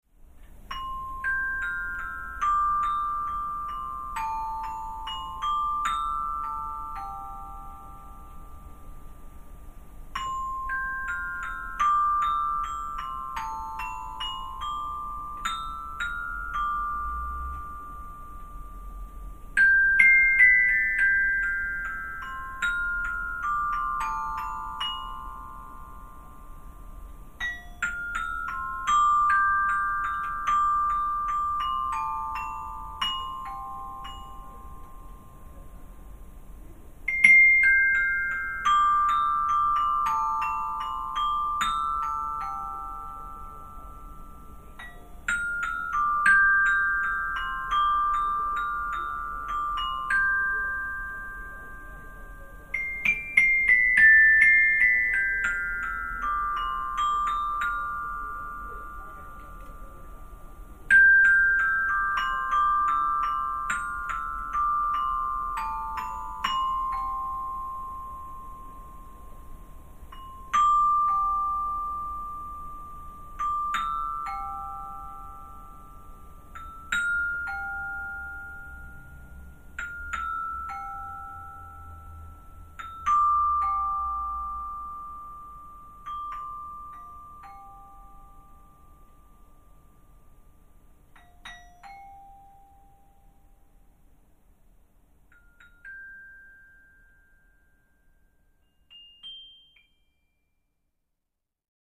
ハーモニックなパーカッションが描き出す繊細でドラマティックな音像
drums, percussion